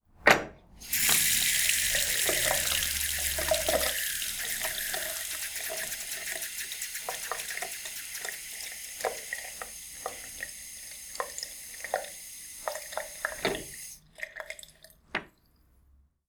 tap-water-2.wav